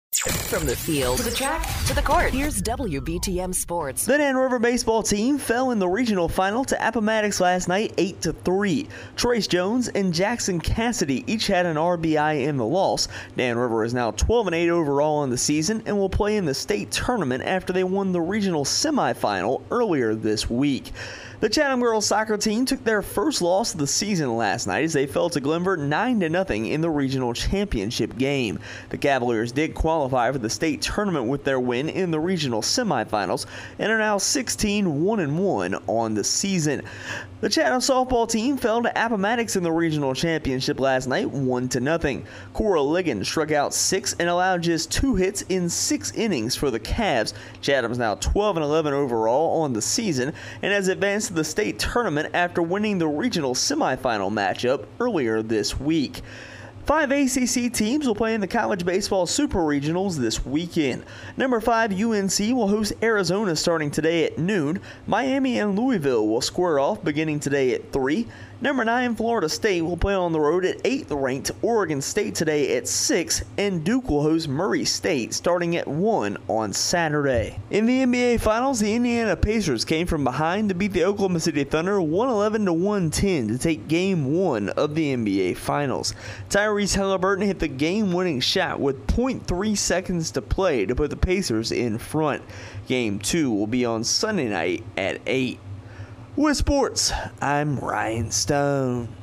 Dan River Baseball, Chatham Girls Soccer Fall in Regional Finals, and More in Our Local Sports Report